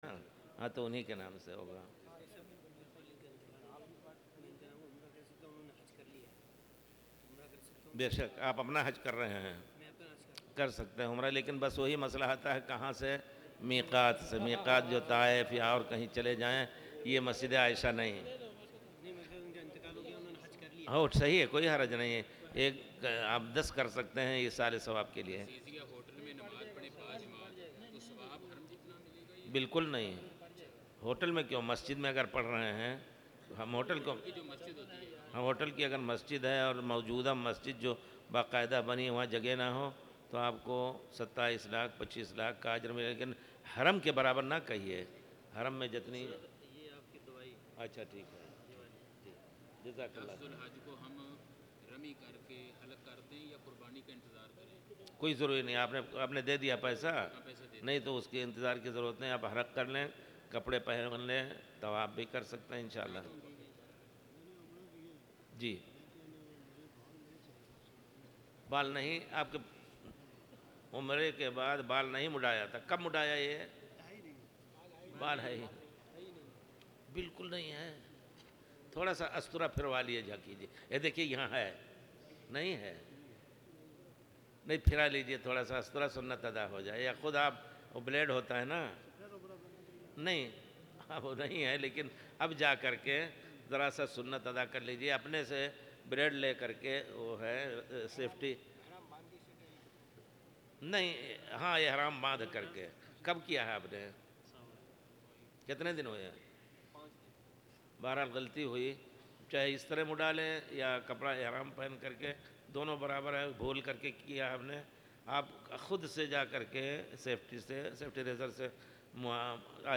تاريخ النشر ٢ ذو الحجة ١٤٣٨ هـ المكان: المسجد الحرام الشيخ